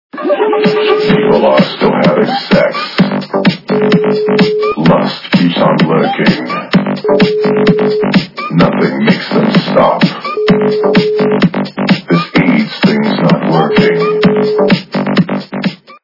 западная эстрада
При заказе вы получаете реалтон без искажений.